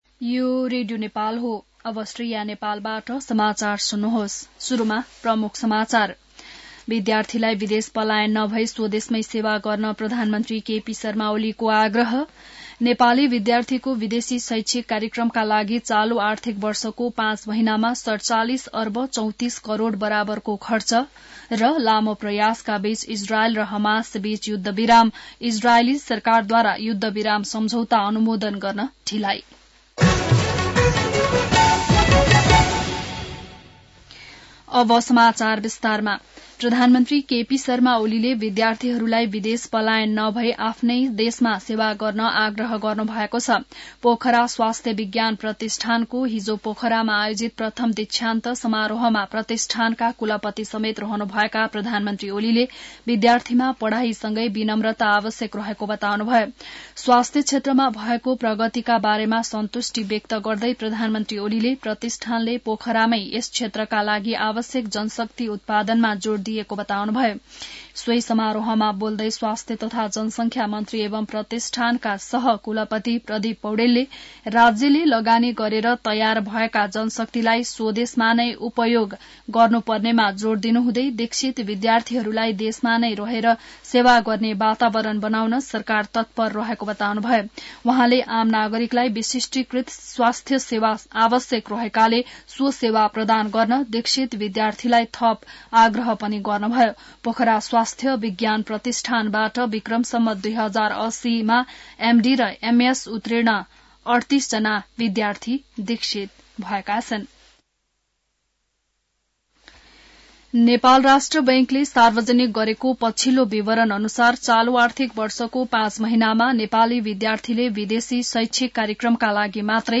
बिहान ९ बजेको नेपाली समाचार : ५ माघ , २०८१